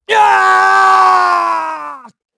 Clause-Vox-Story-Pain_1_kr.wav